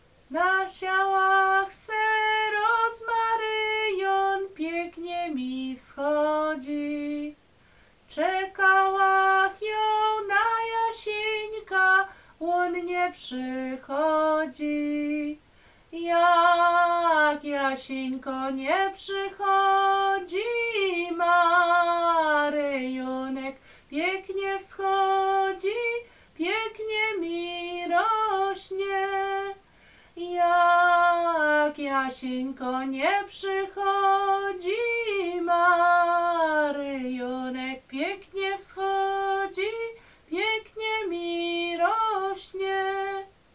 lato kupalskie sobótkowe świętojańskie